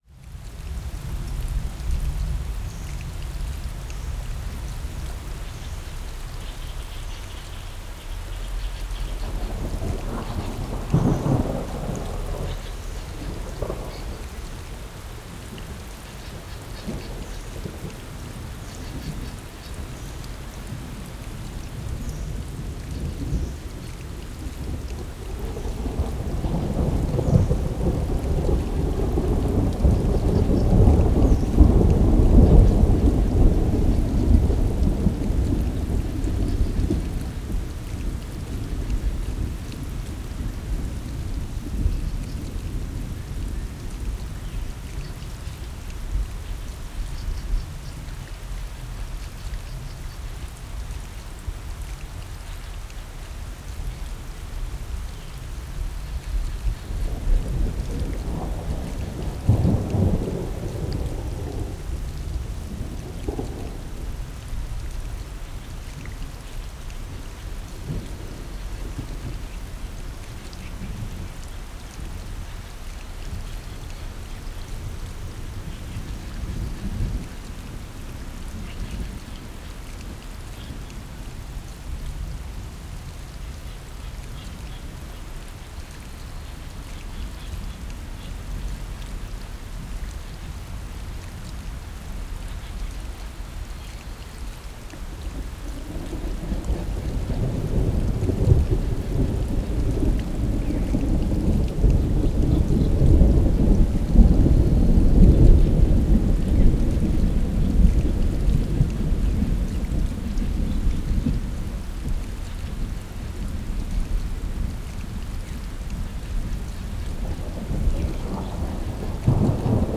copyparty md/au/ambient/Deezer/Deezer - Bruits d'orage anti-stress
08 - Au milieu d'une tempête de pluie.flac